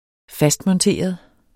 Udtale [ ˈfasdmʌnˌteˀʌ ]